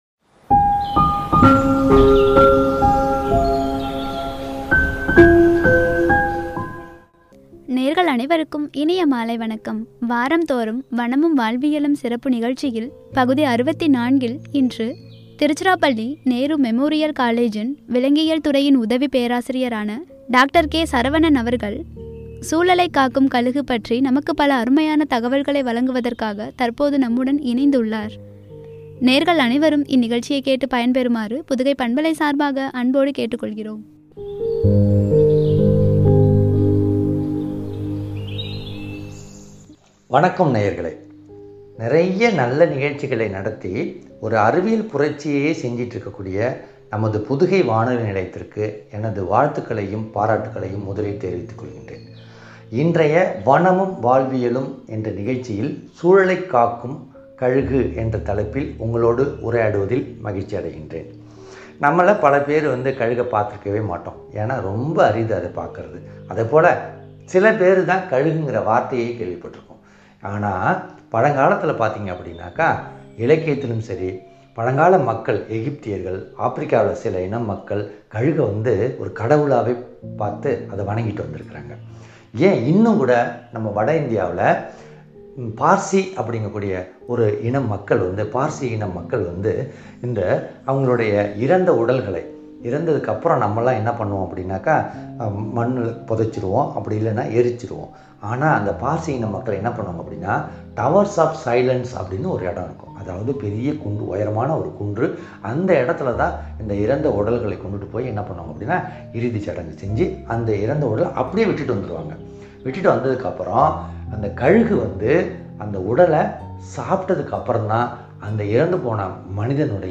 “சூழலை காக்கும் கழுகு” குறித்து வழங்கிய உரை.